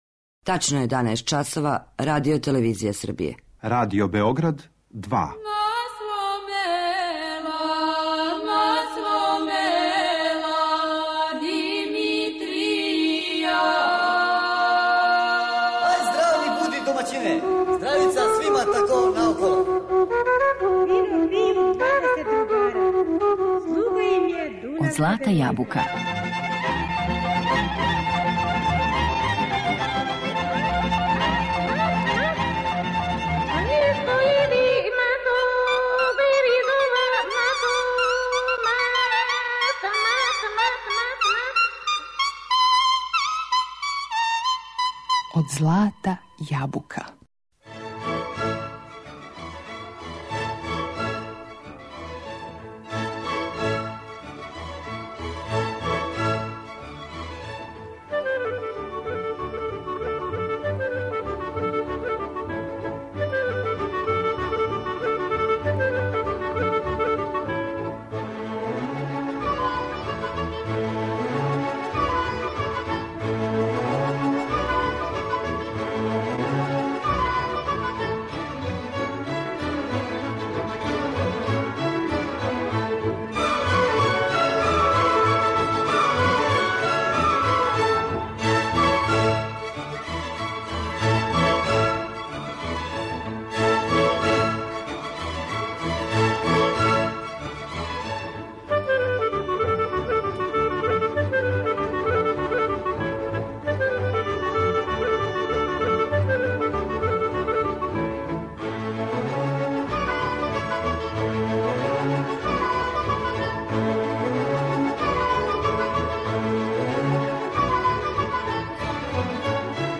Данашњу емисију посветили смо народним песмама у обради.
Слушамо изузетно ретке снимке песама и игара у уметничкој обради, музичке записе из звучног архива Радио Београда.